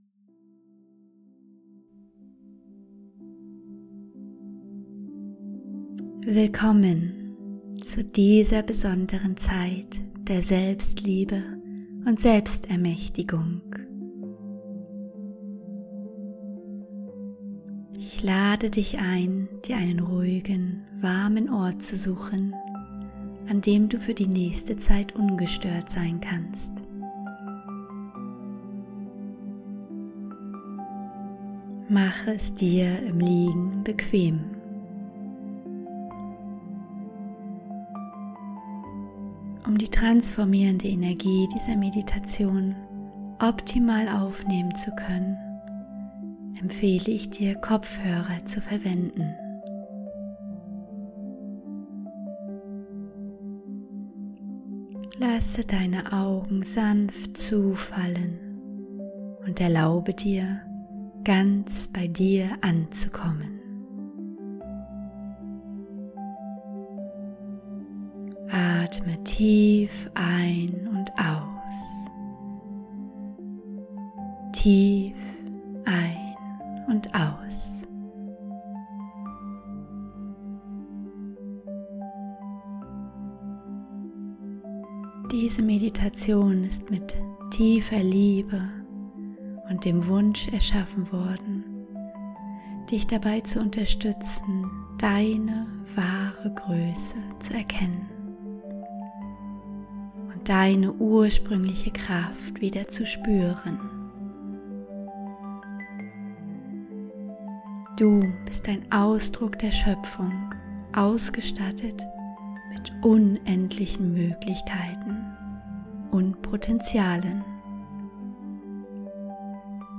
Der summende Ton im Hintergrund ist der Binaurale Ton, der dein Gehirn in den Theta-Zustand bringt